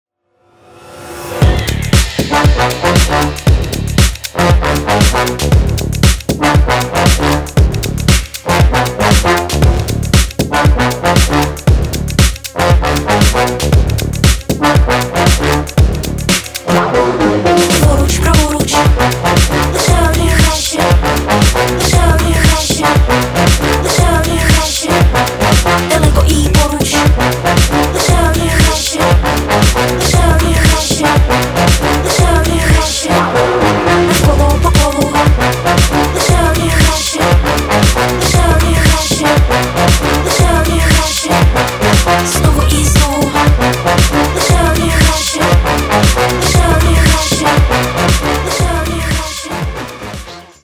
• Качество: 320, Stereo
громкие
мощные басы
Electronica
необычные
мрачные